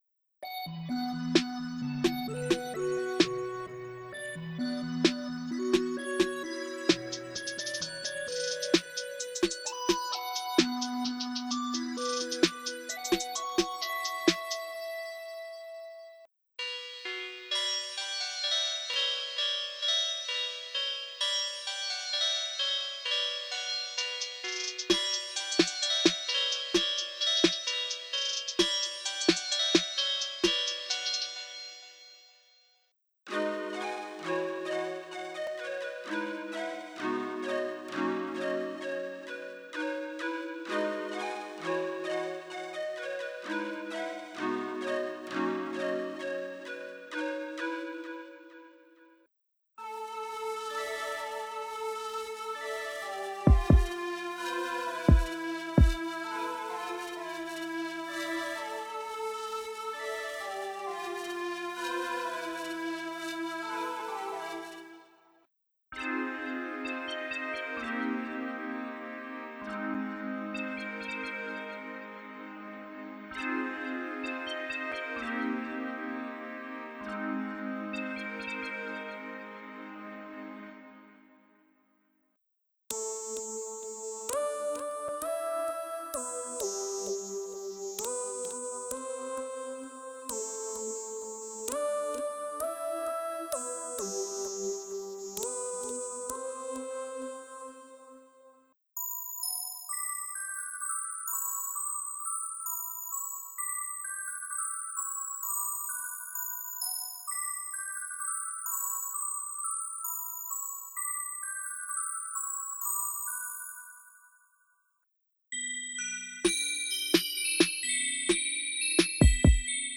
我们为这个项目设计了不同的声音，我们的目标是创造奇怪、不寻常但可玩的声音。
808s，Arps，Bass，Bells
Synth，Fx，Synths，Keys
Leads，Pads，Plucks和Brass。
e.q、hp 和 lp 滤波器控件、镶边器、相位器、延迟、混响和琶音器